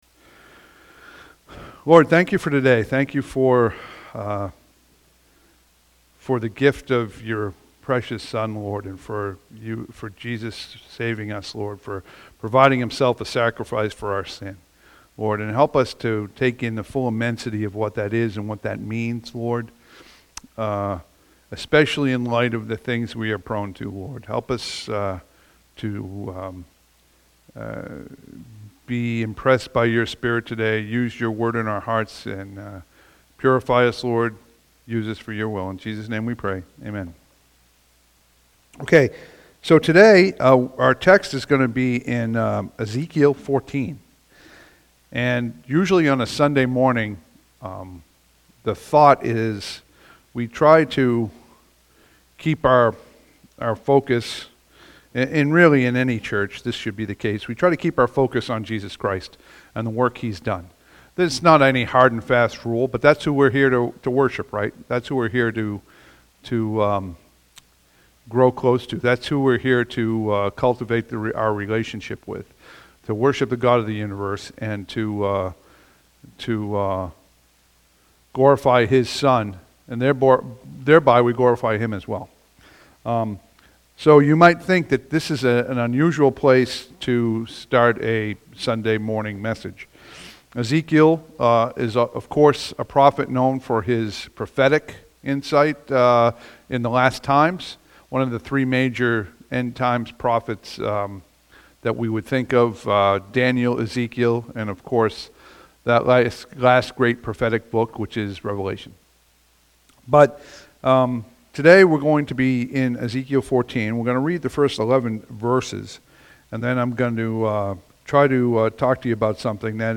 Ezekiel 14:1-11 Service Type: Sunday AM « May 11